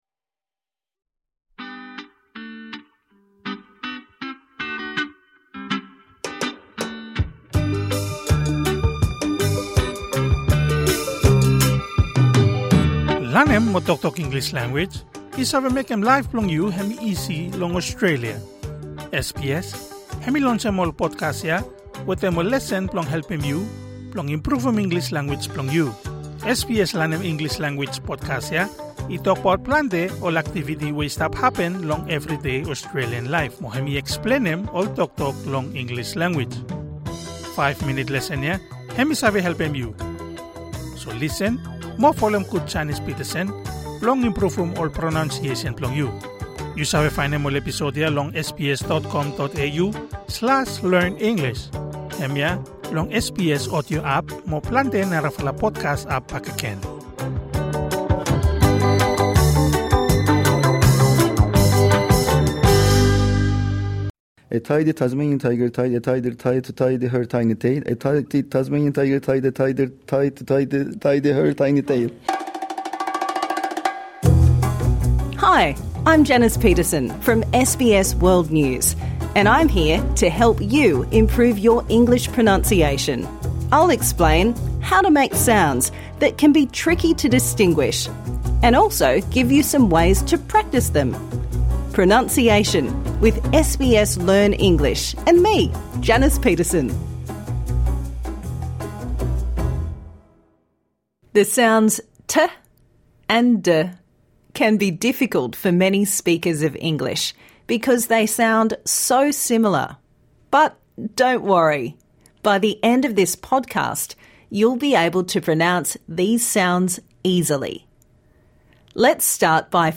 How to improve your English pronunciation